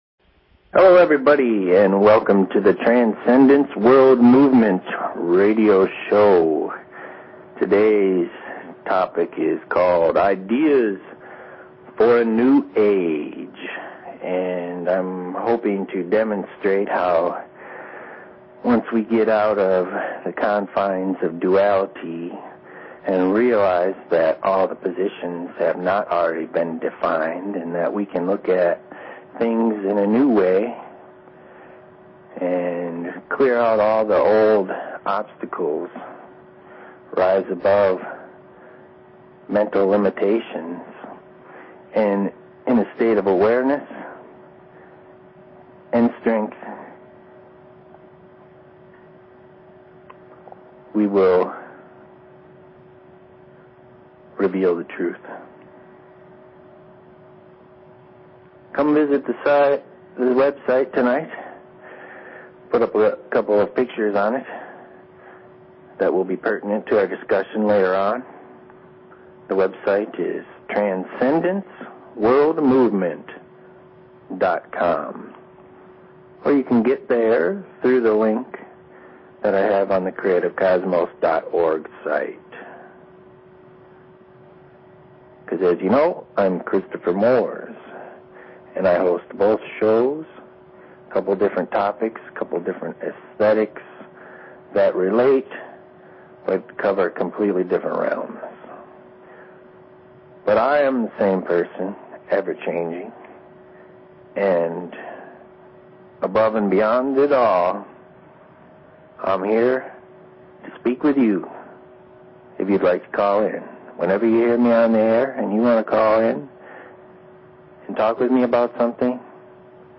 Talk Show Episode, Audio Podcast, TWM and Courtesy of BBS Radio on , show guests , about , categorized as